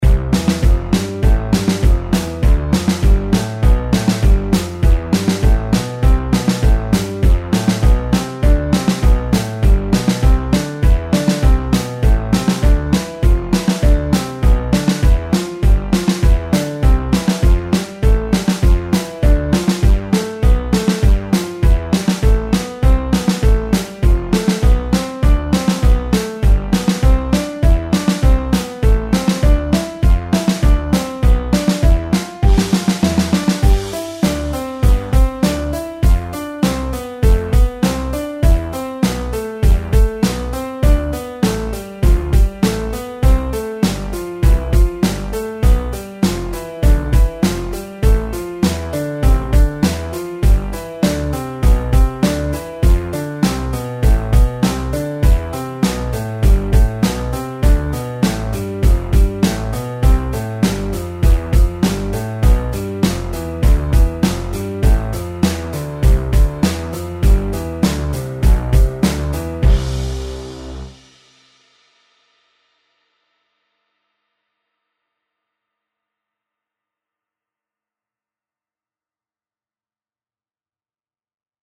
Первым унас идет простое упражнение, перебором в одну с торону, а потом обратно.
Левой рукой начинаем играть в "Большой октаве" ноты, штили которых направлены вниз, а правой рукой начинаем играть в "Малой октаве" ноты, штили которых направлены вверх!
Скачивайте мою аранжировку и тренируйтесь с удовольствием: 🠆СКАЧАТЬ🠄 А если вы считаете, что данная скорость (100BPM) для вас очень быстрая, то пишите мне в Контакте или в Телеграме и я замедлю аранжировку по вашему требованию.